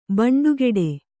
♪ baṇḍugeḍe